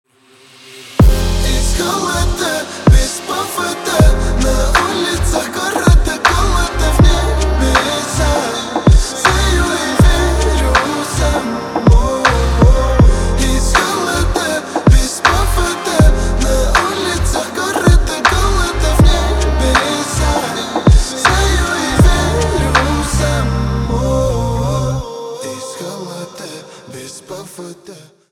русский рэп
битовые , басы , пианино
труба